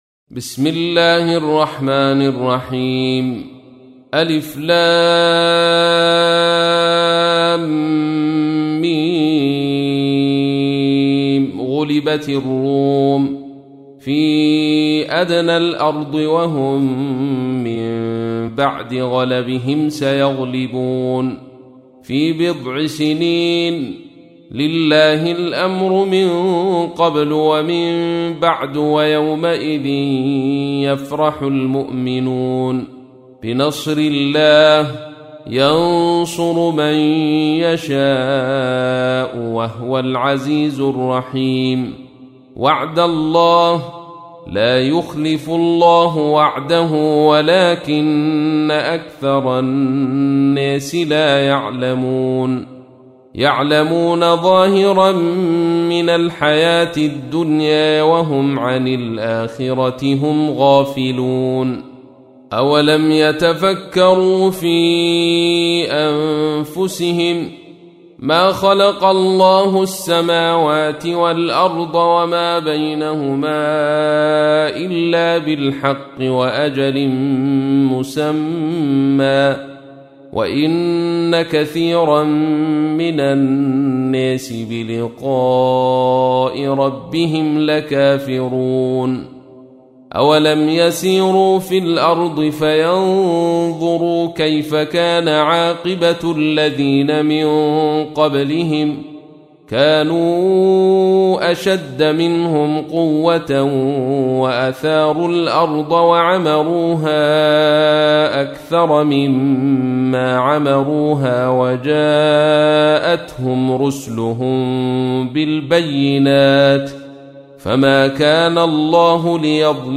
تحميل : 30. سورة الروم / القارئ عبد الرشيد صوفي / القرآن الكريم / موقع يا حسين